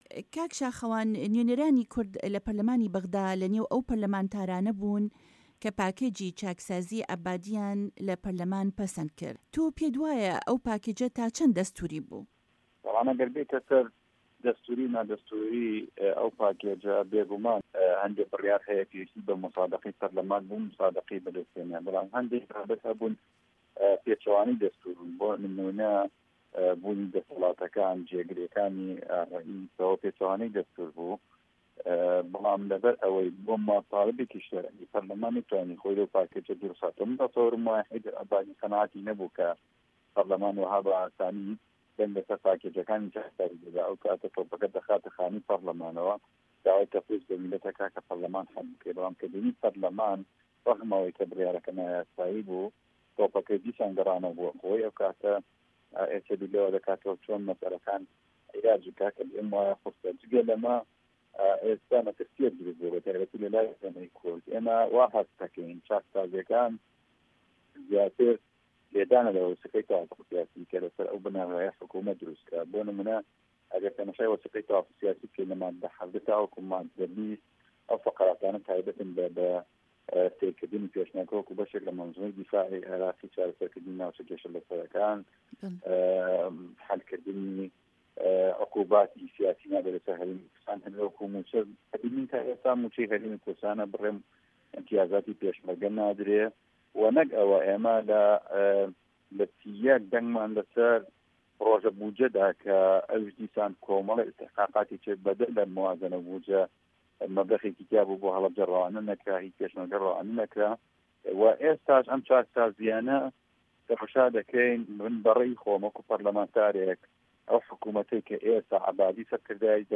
شاخەوان عەبدوڵا، ئەندامی پەرلەمانی عیراق لە پەیوەندییەکدا لەگەڵ بەشی کوردی دەنگی ئەمەریکا لەو بارەیەوە گوتی بەداخەوە بەهۆی ئەو ڕەوشەی لە کوردستان دروستبووە، هێندەی خەریکی یەکن سەرکردە سیاسییەکانی کورد،ئاگایان لە بەغدا نەماوە.
وتووێژ لەگەڵ شاخەوان عەبدوڵا